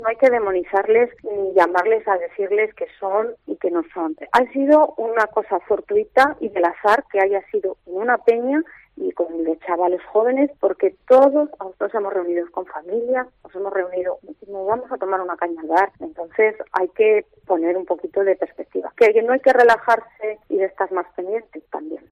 La alcaldesa de La Cisterniga, Patricia González, en Herrera en COPE Valladolid